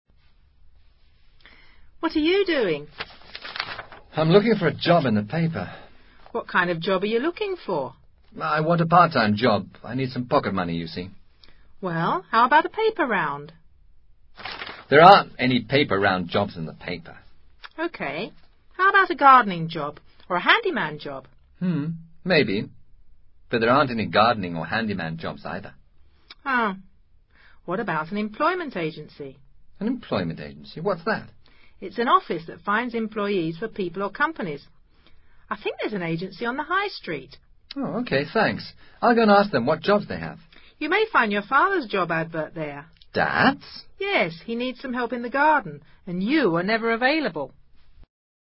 Diálogo cuyo tema son los empleos ocasionales y de medio tiempo.